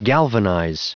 Prononciation du mot galvanize en anglais (fichier audio)